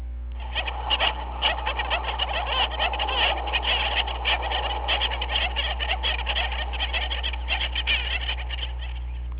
Penguin Sounds
penguin-2-sounds.wav